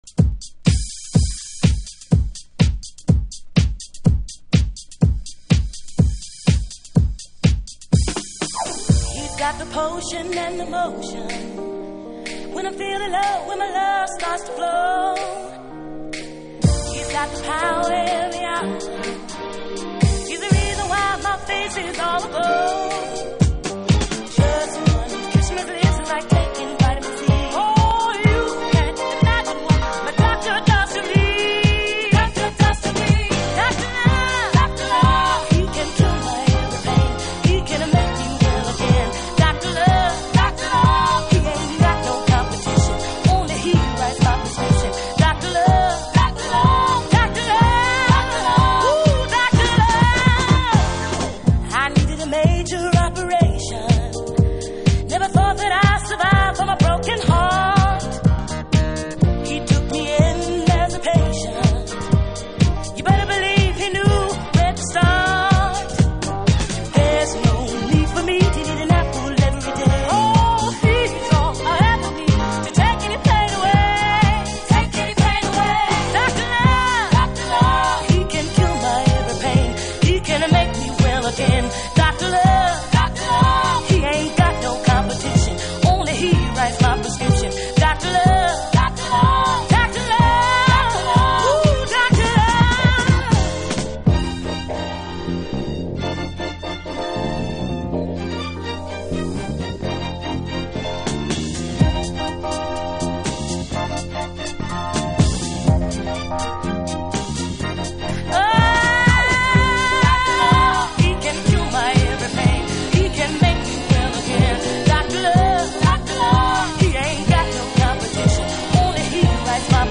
TOP > Alt Disco / Boogie > VARIOUS